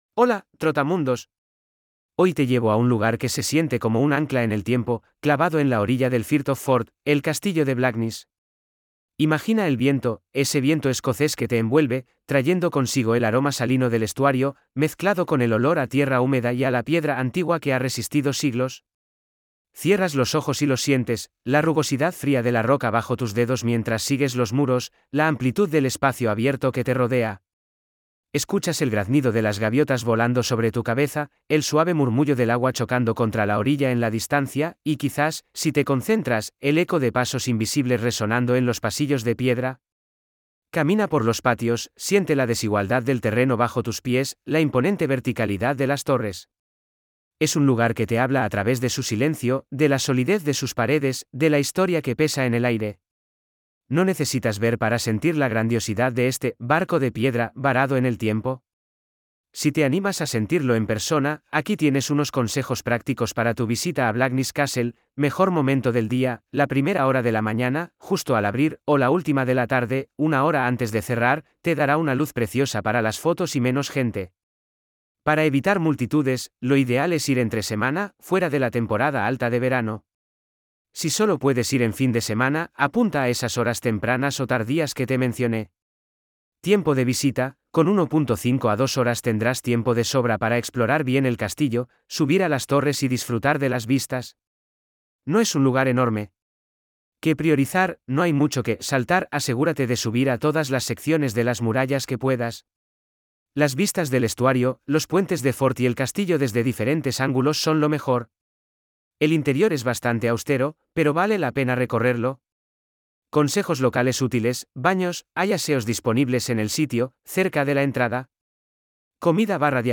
🎧 Guías de audio disponibles (2) Guía de Experiencia Emocional (ES) 🔗 Abrir en una nueva pestaña Información práctica (ES) 🔗 Abrir en una nueva pestaña